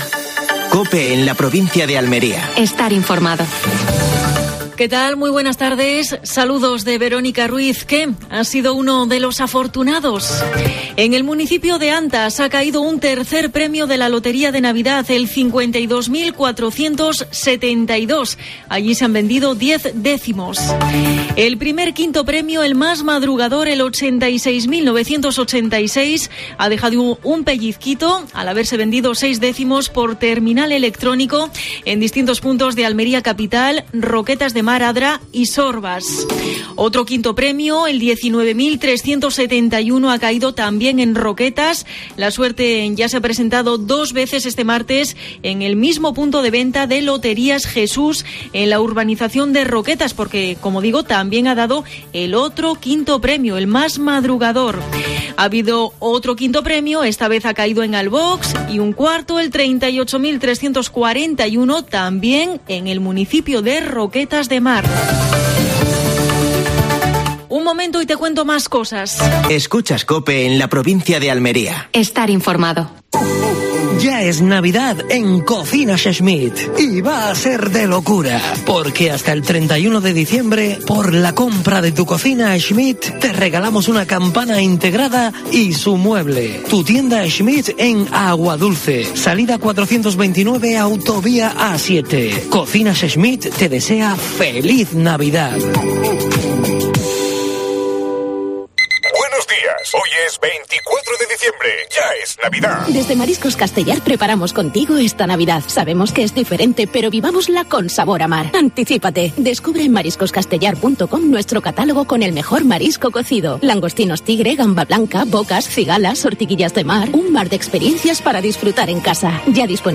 Actualidad en Almería. El Gordo pasa de 'puntillas' por la provincia. Entrevista